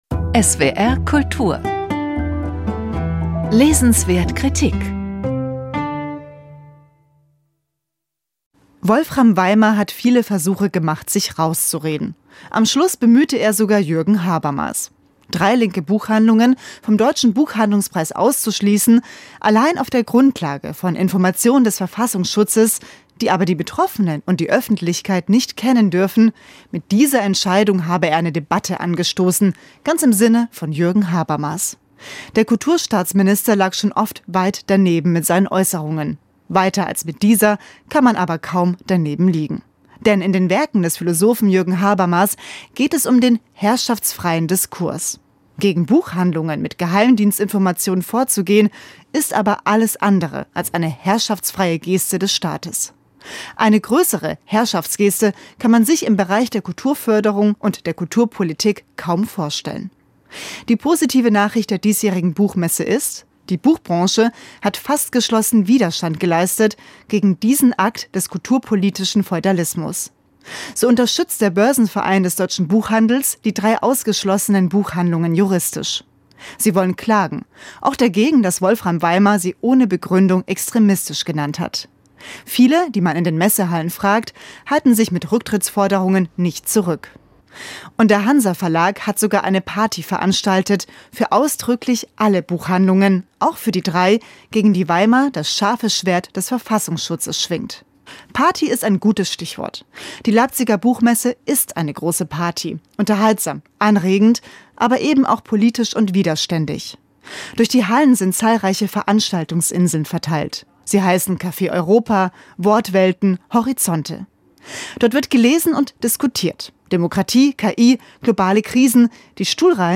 Abschlusskommentar
leipziger-buchmesse-2026-abschlusskommentar.mp3